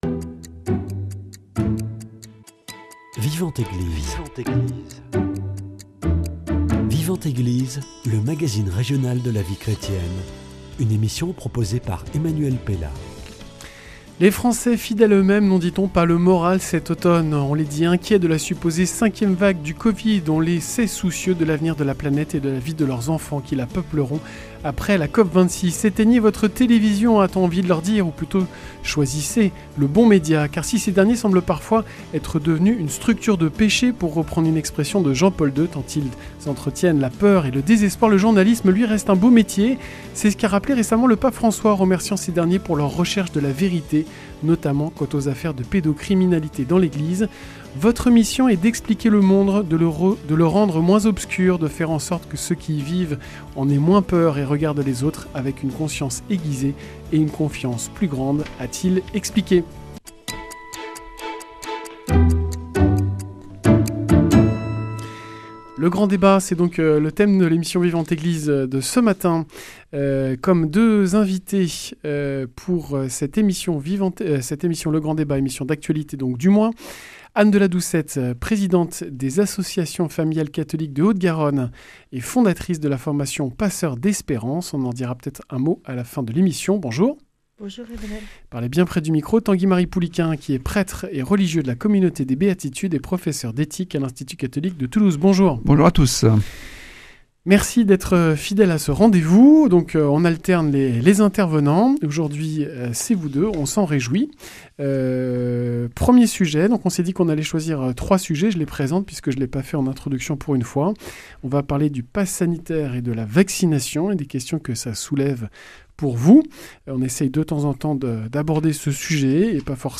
Le grand débat